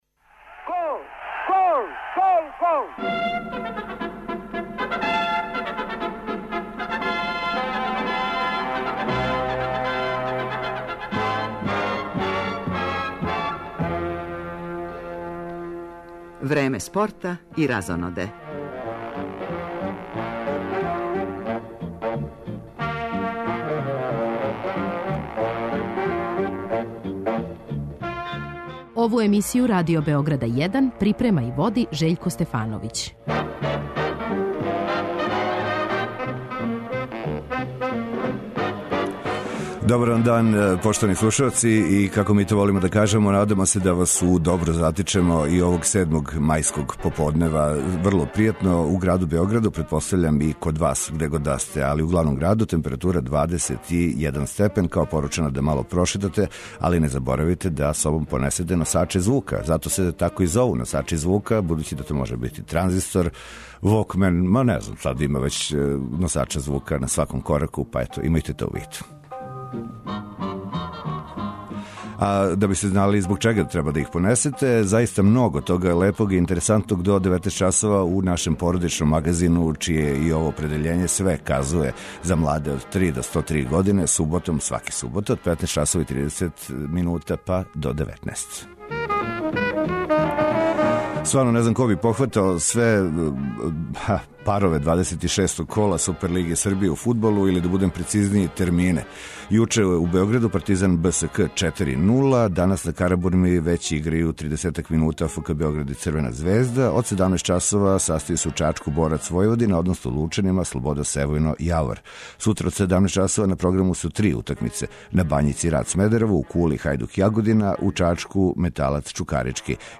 У породичном магазину и ове суботе биће доста фудбала. Поред комбинованих преноса утакмица 26. кола Супер лиге Србије, пратићемо и кретање резултата на утакмицама немачког и енглеског шампионата.